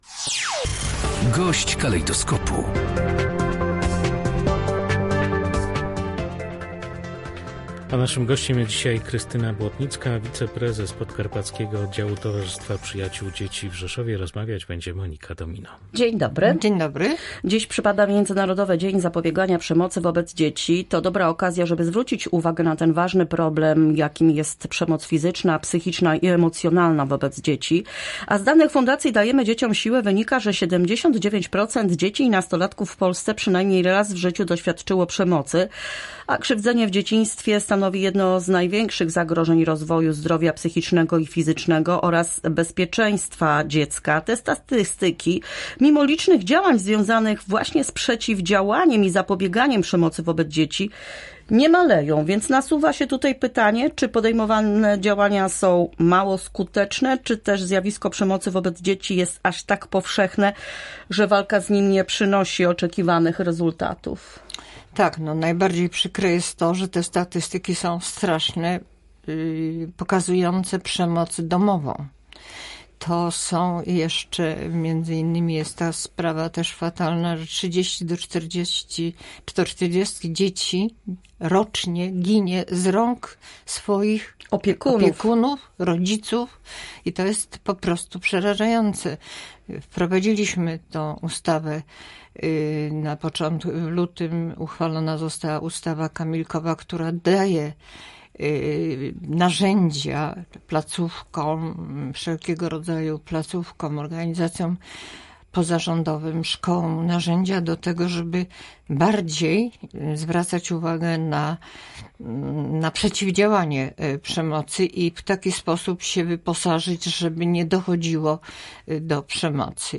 GOŚĆ DNIA. Przemoc wobec dzieci. Zatrważające statystyki